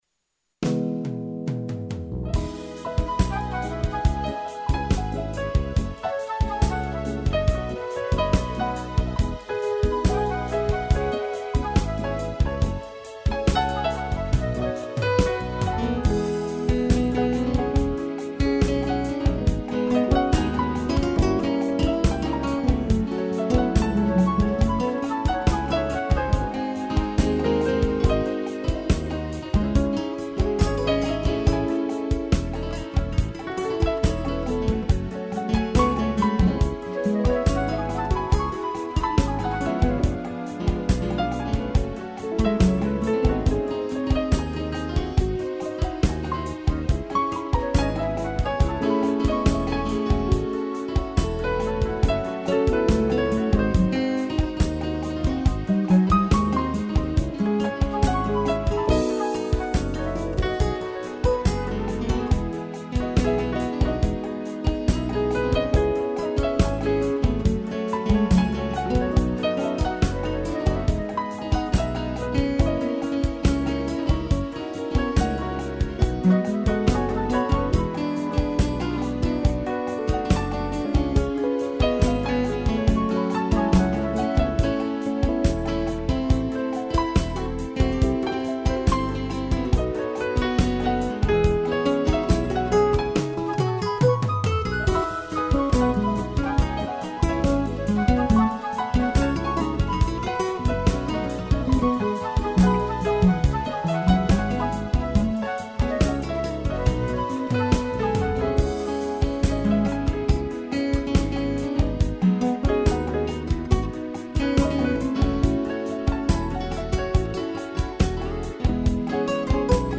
piano e flauta
(instrumental)